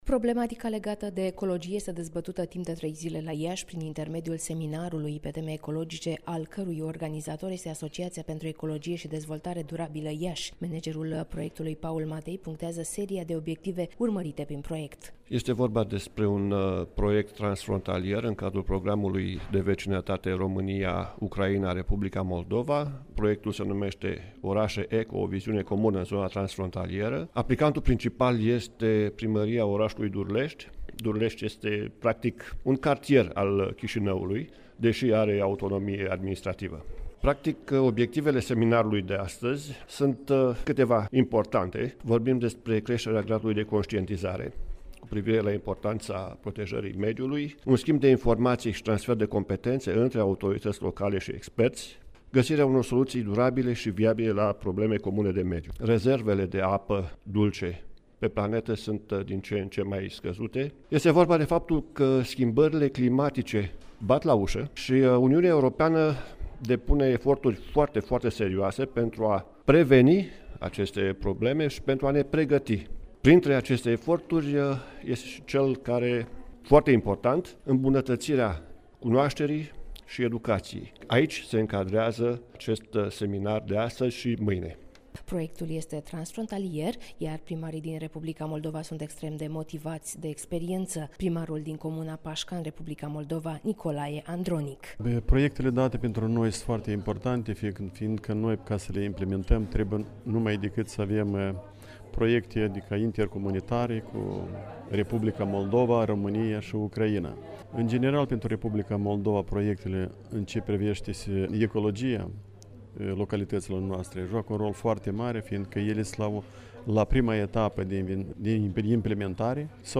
(REPORTAJ) La Iași a început un seminar despre ecologie